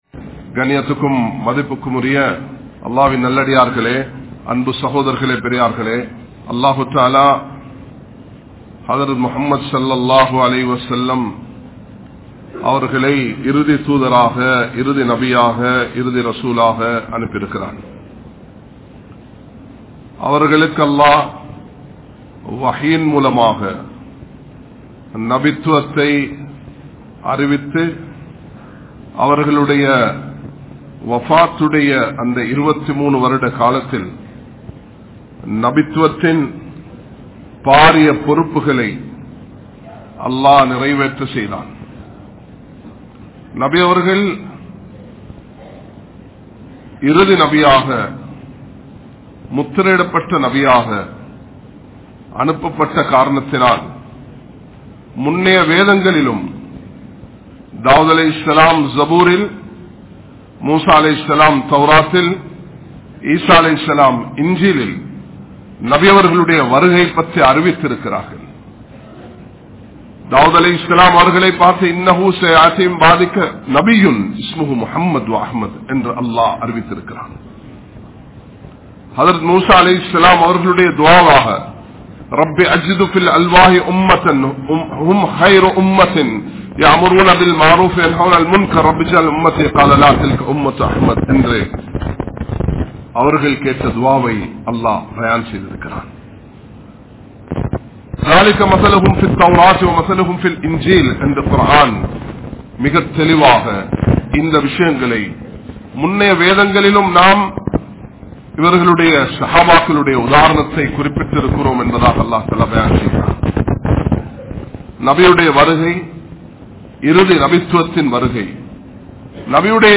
Arivin Avasiyam (அறிவின் அவசியம்) | Audio Bayans | All Ceylon Muslim Youth Community | Addalaichenai
Makkah Jumua Masjidh